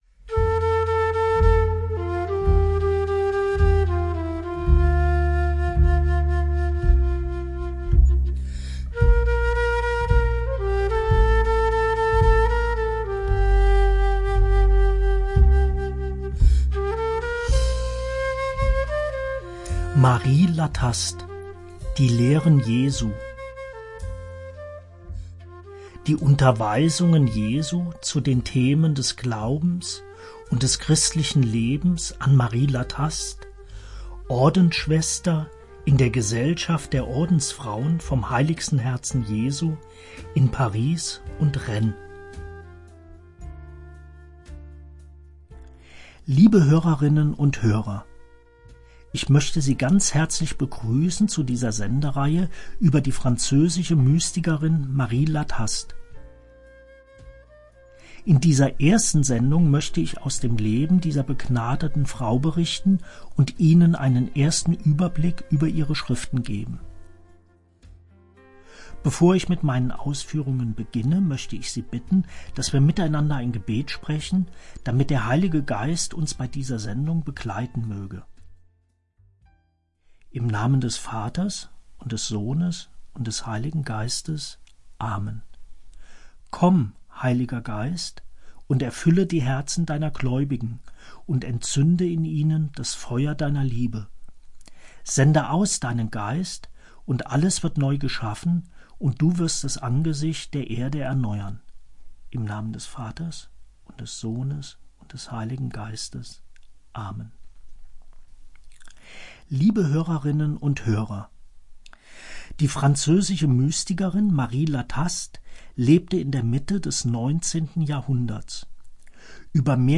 liest persönlich aus diesen Schriften, die er musikalisch umrahmt.